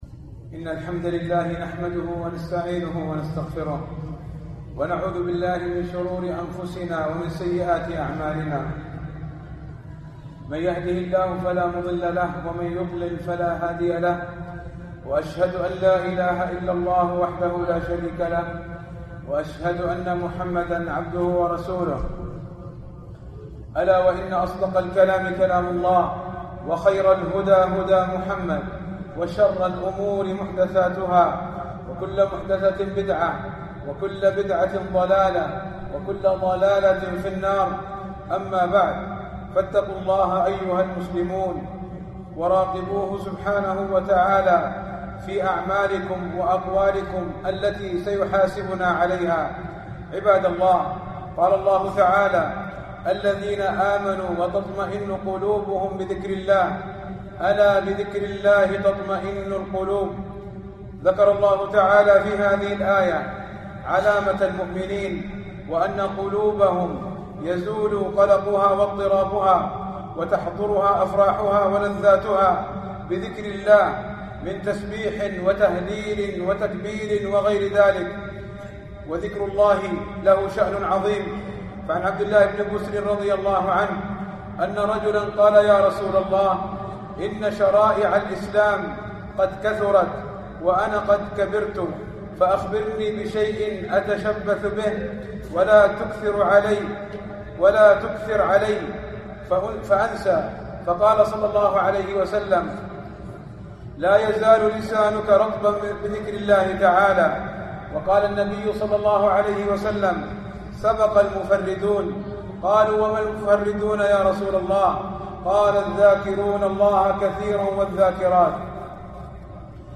القسم: من مواعظ أهل العلم